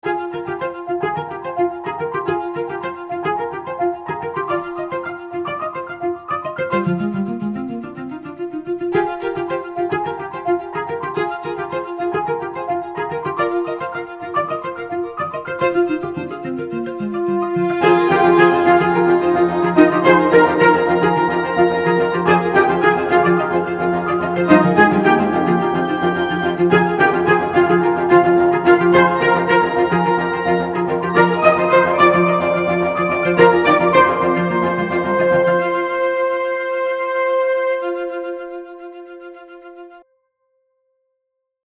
A collection of symphonic tracks